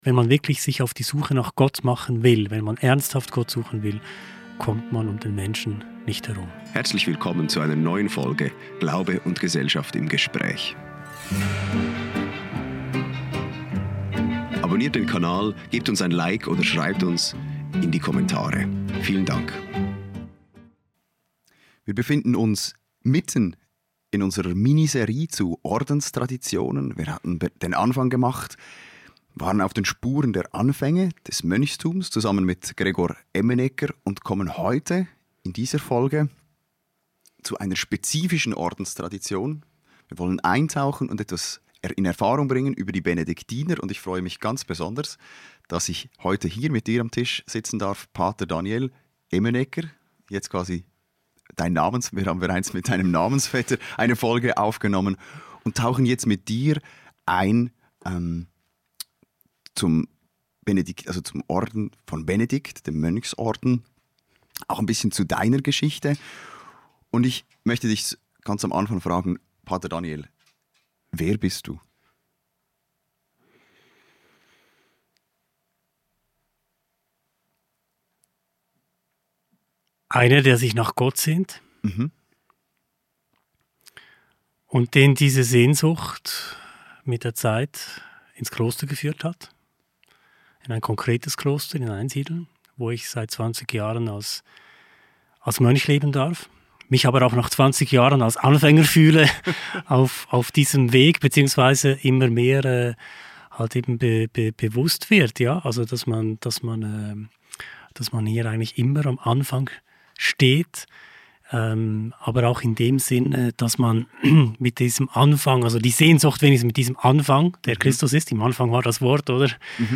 Ein Gespräch über die Benediktiner und das Mönchsleben mit einem, "der Gott sucht".